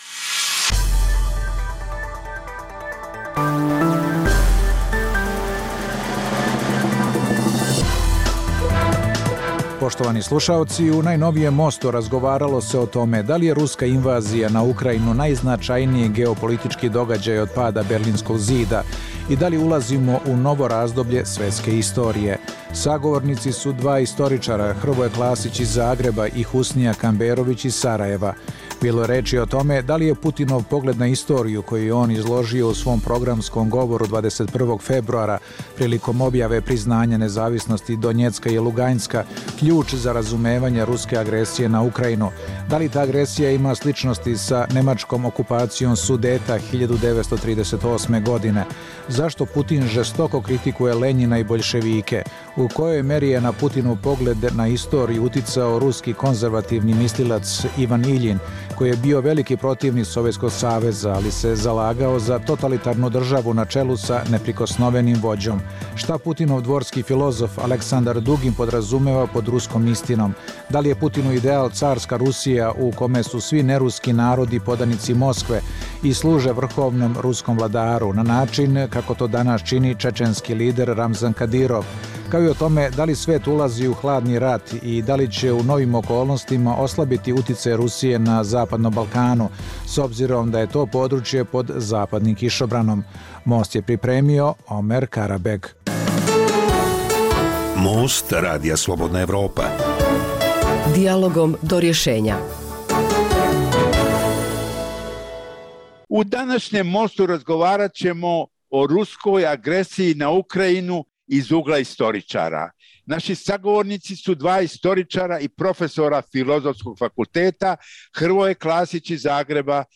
U najnovijem Mostu Radija Slobodna Evropa razgovaralo o ruskoj agresiji na Ukrajinu iz ugla istoričara. Sagovornici su bili dva istoričara i profesora Filozofskog fakulteta